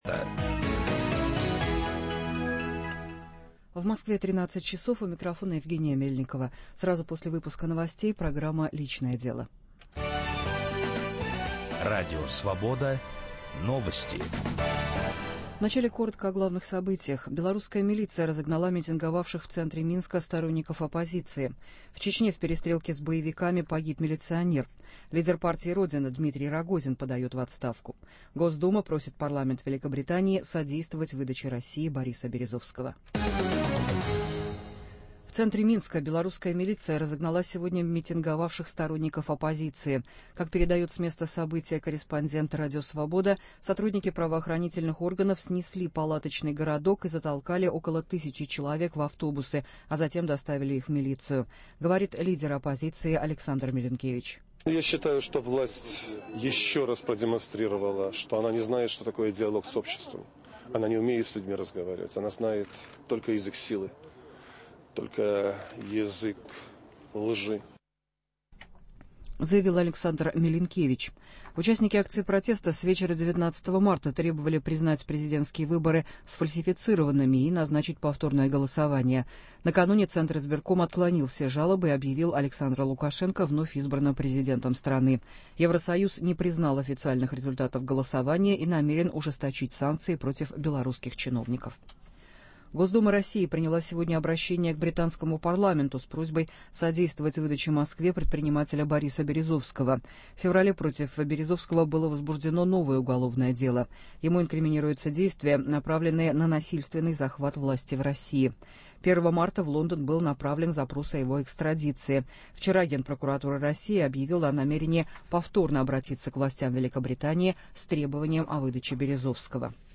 В прямом эфире разговор о целомудрии. Слово это почти ушло из обиходного языка, потеряло ли вместе с тем смысл и само понятие?